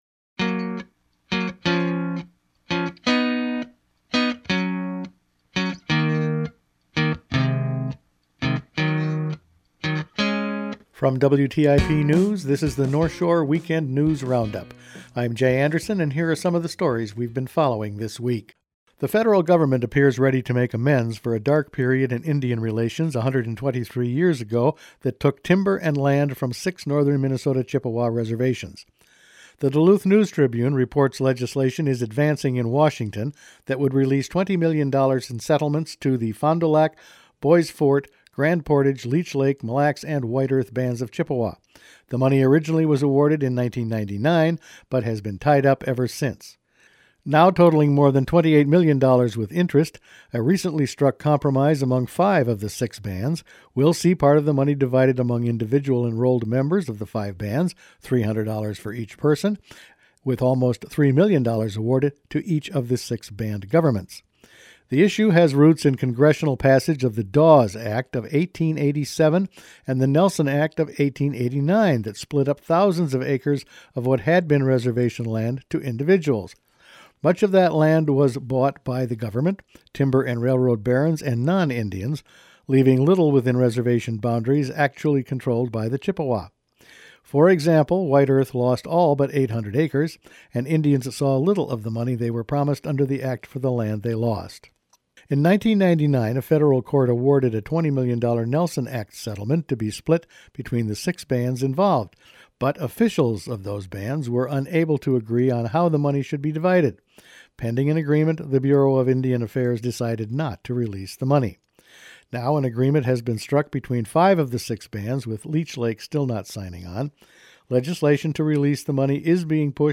Each weekend WTIP news produces a round up of the news stories they’ve been following this week. Northern Indian bands will receive financial settlements, Northshore Mining fines, Forest Service spring fire preparations in a time of drought and another detour on the way to Ely…all in this week’s news.